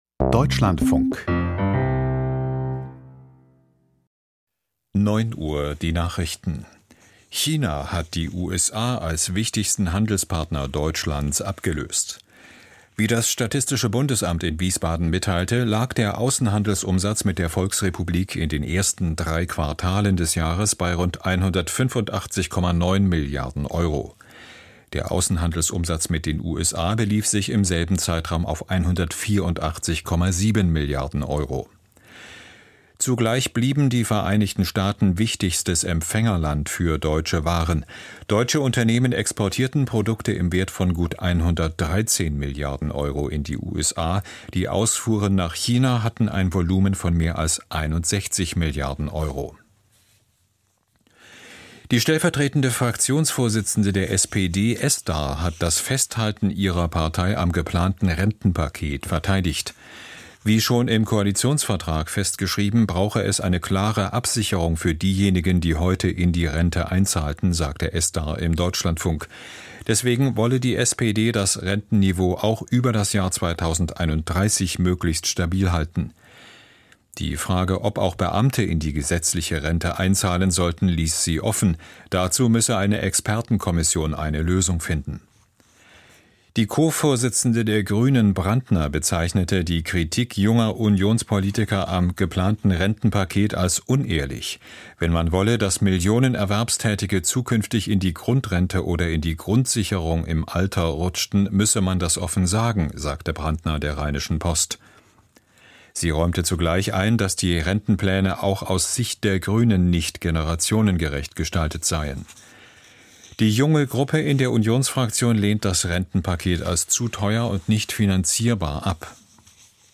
Die Nachrichten vom 19.11.2025, 09:00 Uhr
Aus der Deutschlandfunk-Nachrichtenredaktion.